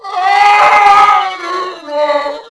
ONFIRE1.WAV